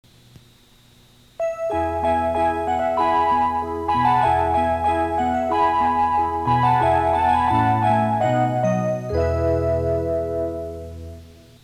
２３０００系「伊勢志摩ライナー」と、２１０００系・２１０２０系「アーバンライナー」の自動放送では“各駅到着前始発駅発車前に違う車内チャイム”が流れます。